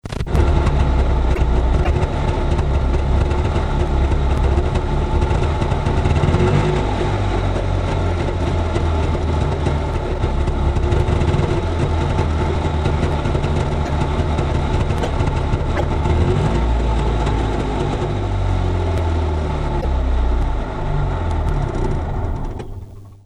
Trabistart